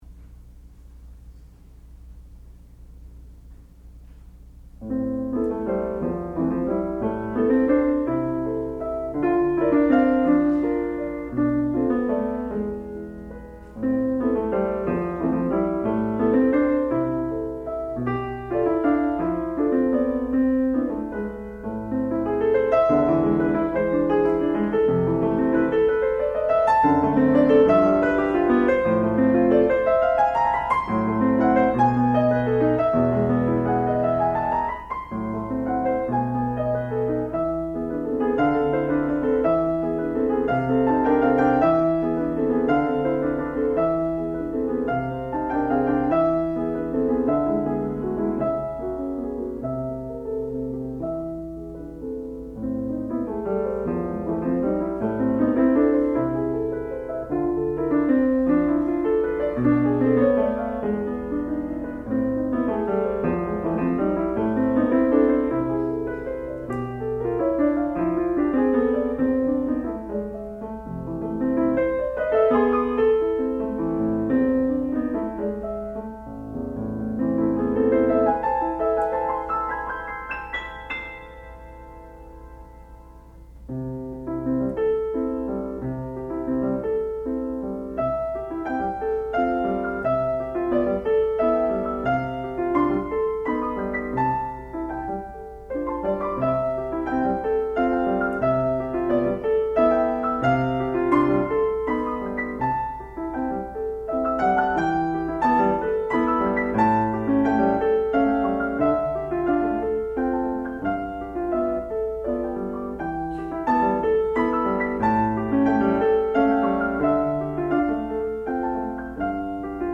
sound recording-musical
classical music
Advanced Recital
piano